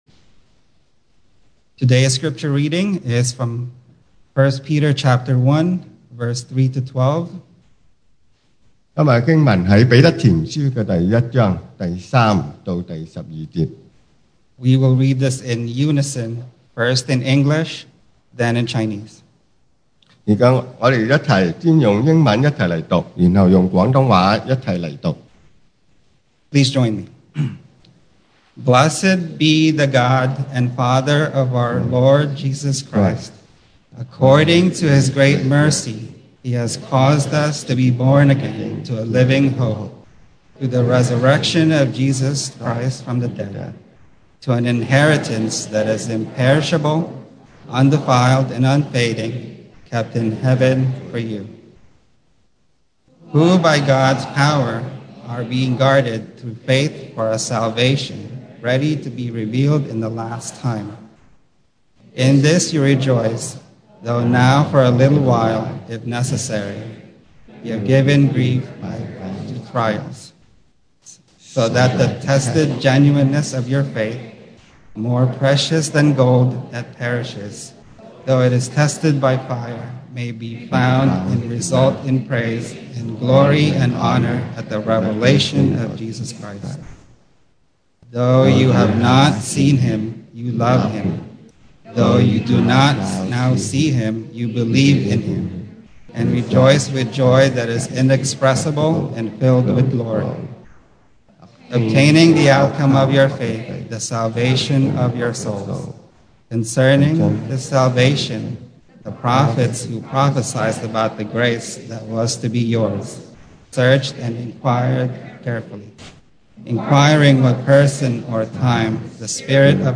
Series: 2021 sermon audios
Service Type: Sunday Morning